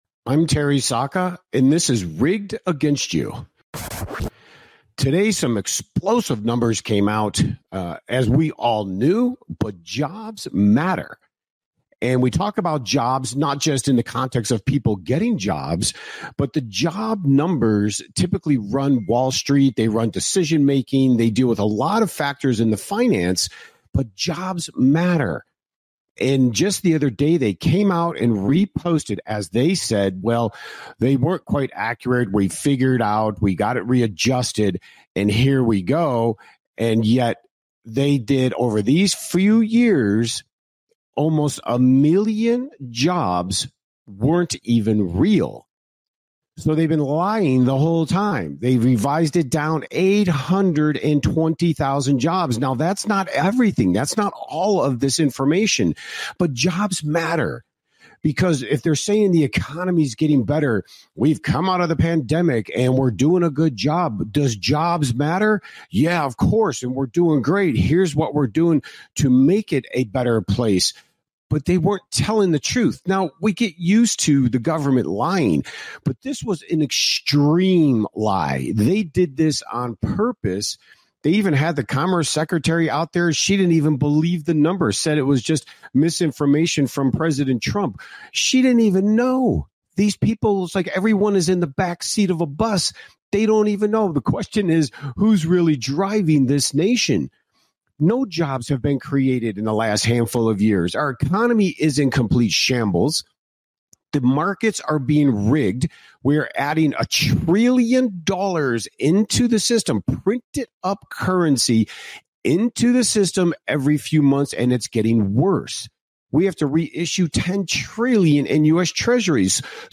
Rigged Against You Talk Show